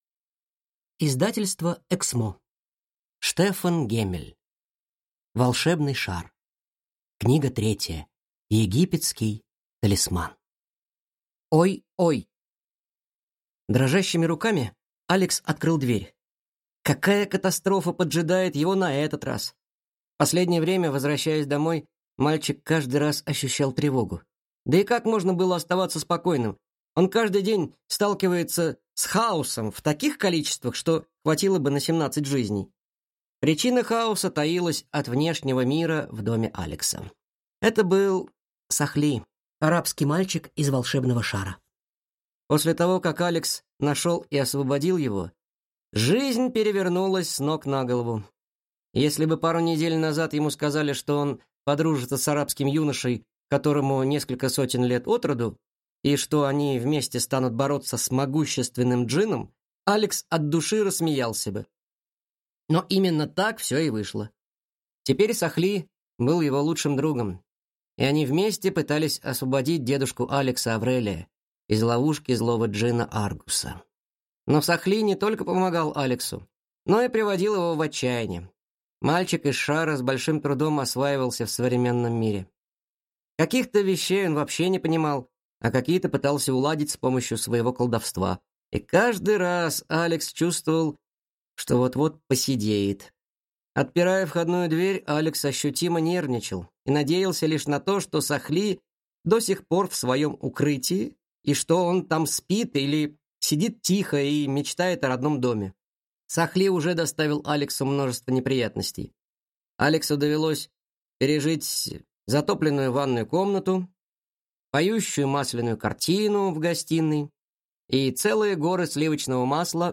Аудиокнига Египетский талисман | Библиотека аудиокниг